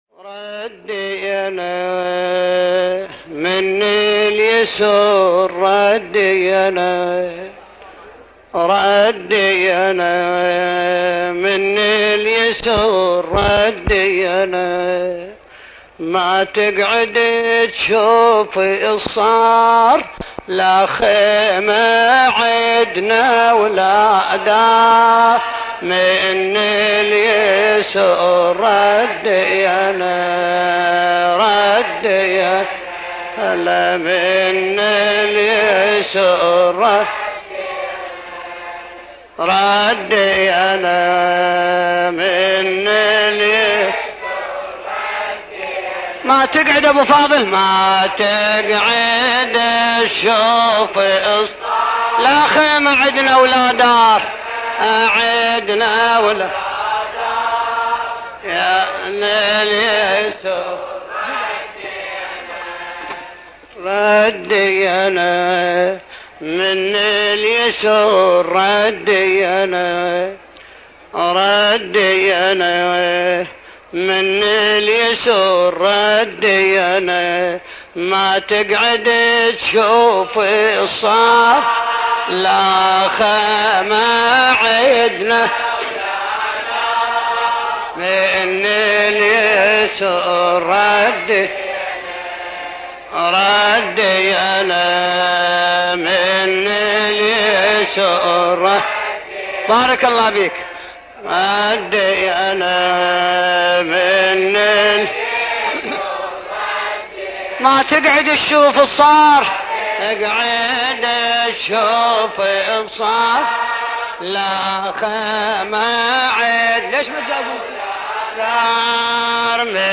المراثي
القارئ : حمزة الزغير